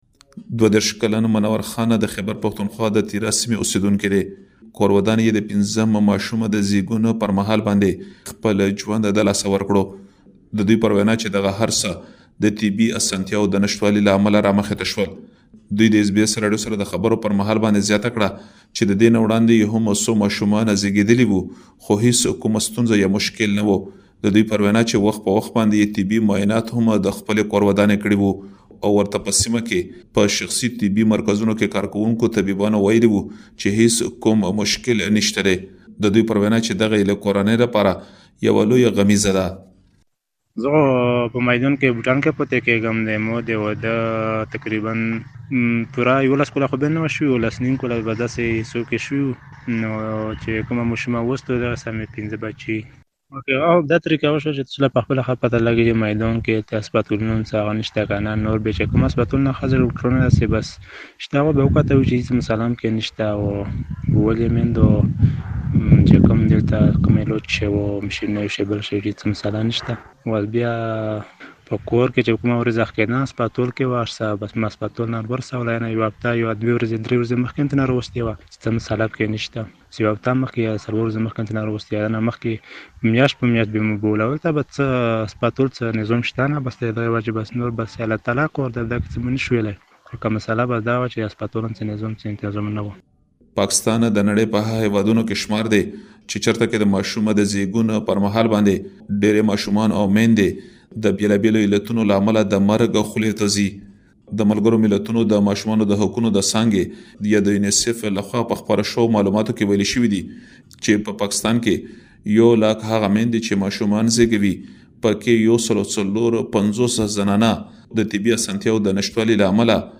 د قبایلو مشران د پاکستان له حکومت څخه د امن ترڅنګ د څه باندې ۶۰ زرو وران شویو کورونو تاوان غواړي. مهرباني وکړئ لا ډېر معلومات دلته په رپوټ کې واورئ.